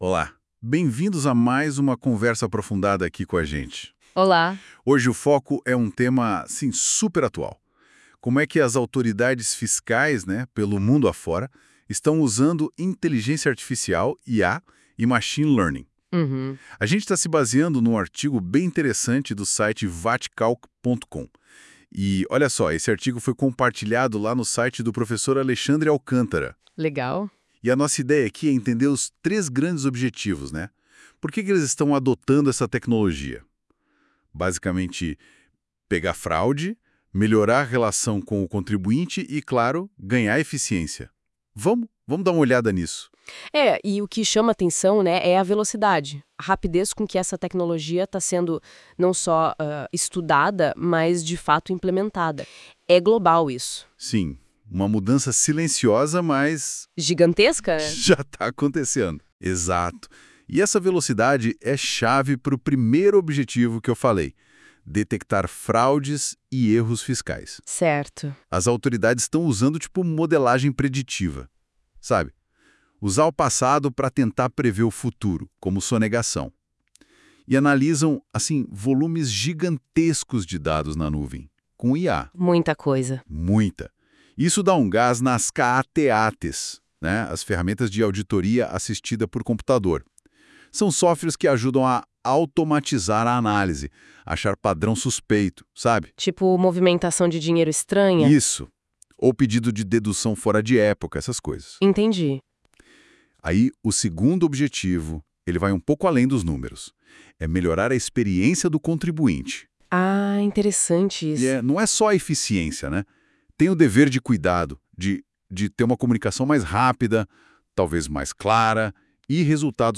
Confira o podcast com um ótimo resumo do artigo, que geramos usando IA